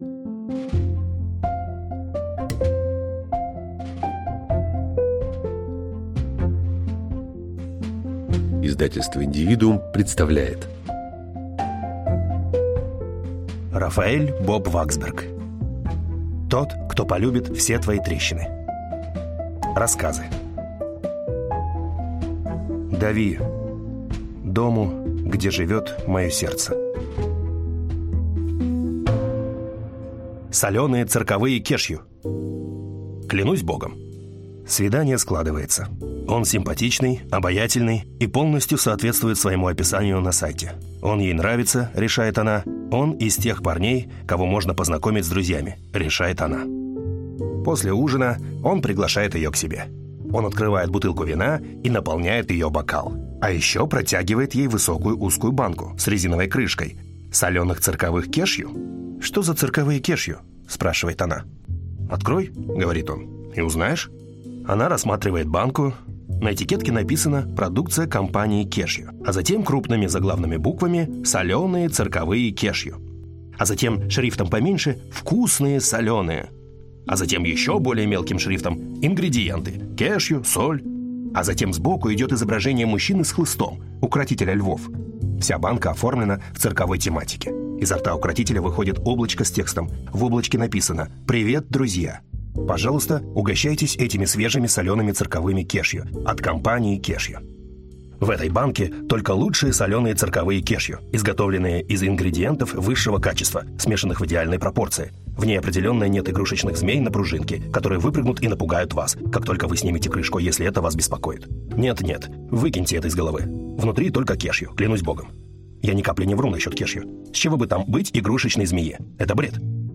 Аудиокнига Тот, кто полюбит все твои трещины | Библиотека аудиокниг